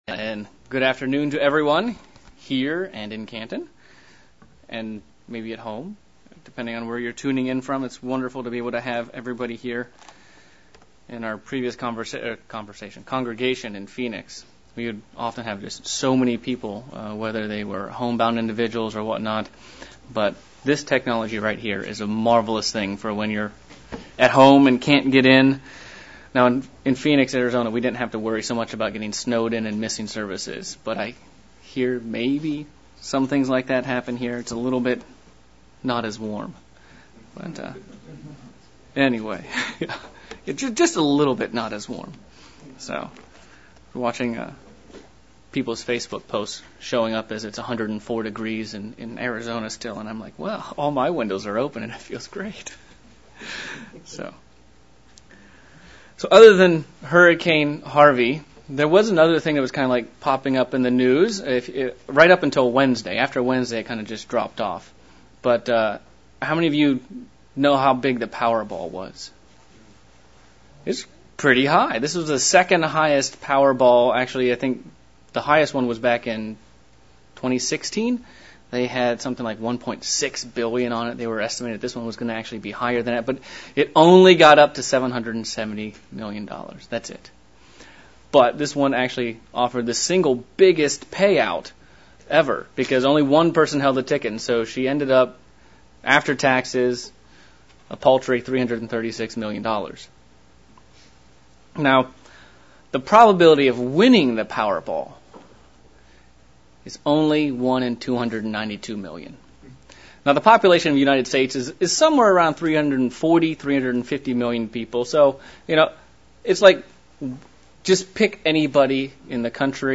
We all make decisions in life, but what do we base them on? A sermon examining the decisions made by those in the Bible and what we can learn from them.